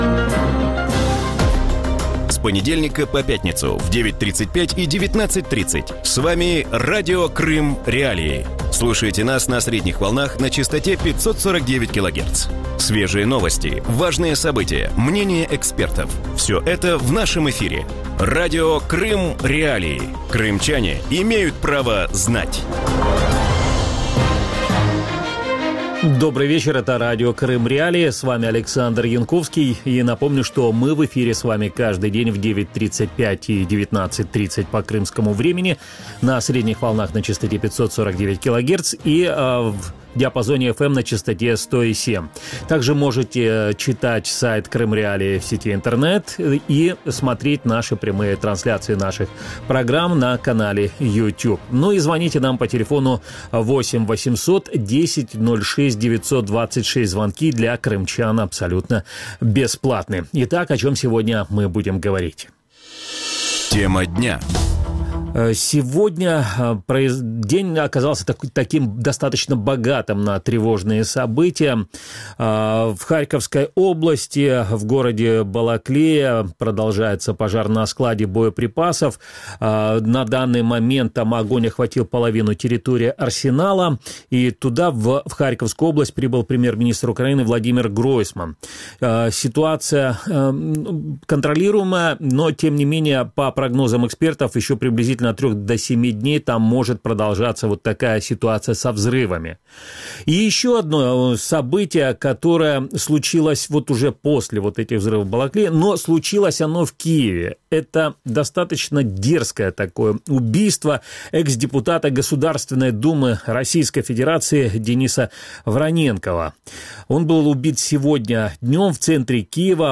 У вечірньому ефірі Радіо Крим.Реалії обговорюють вбивство колишнього російського депутата Дениса Вороненкова в центрі Києва. Кому може бути вигідна смерть екс-депутата Держдуми, який давав свідчення проти президента-утікача Віктора Януковича?